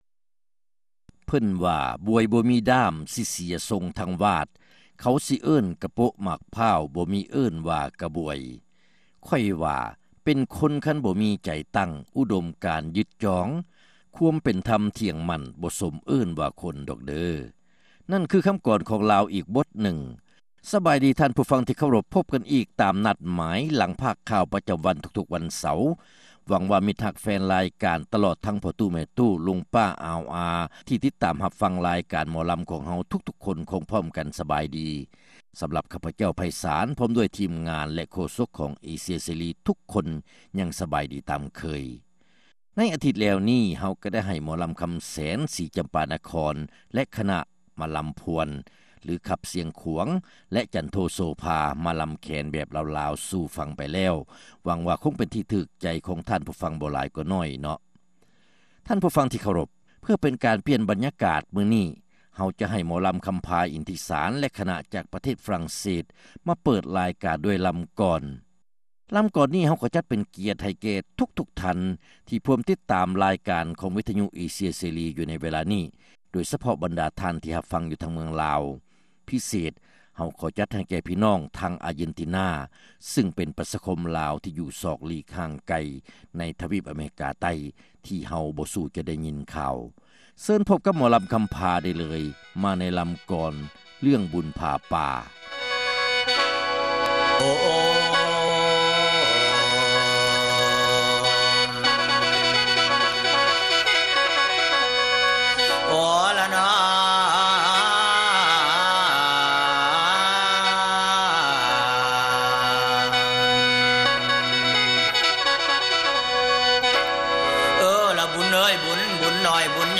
ຣາຍການໜໍລຳ ປະຈຳສັປະດາ ວັນທີ 11 ເດືອນ ສິງຫາ ປີ 2006